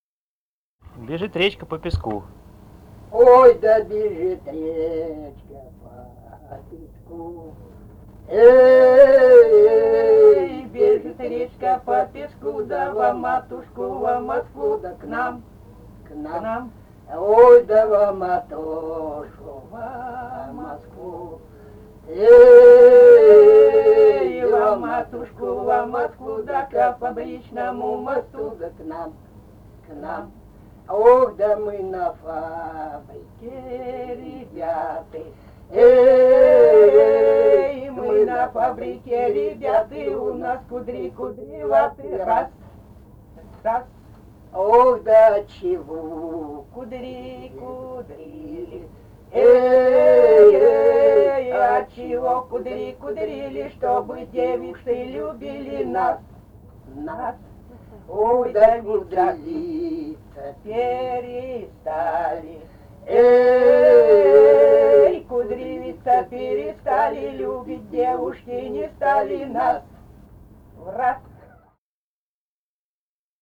Этномузыкологические исследования и полевые материалы
Алтайский край, Заимка Борзовая (округ г. Барнаула), 1967 г. И1016-13